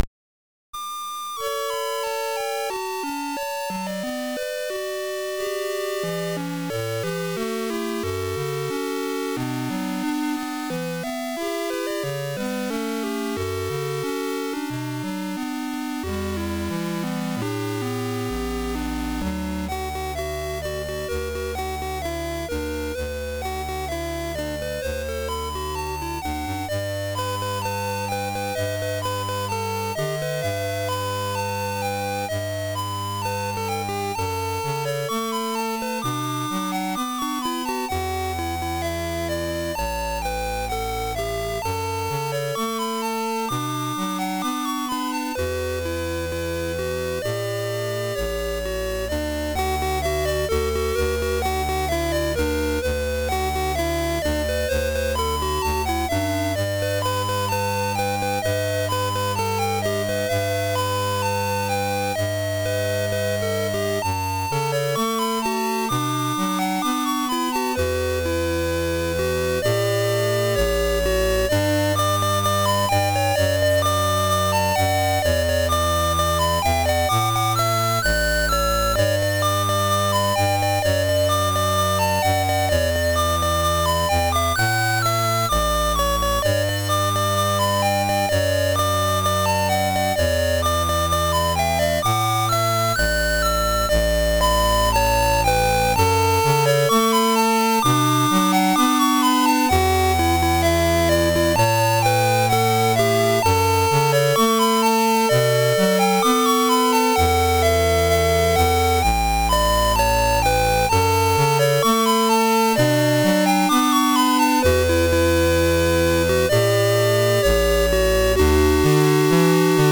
Commodore SID Music File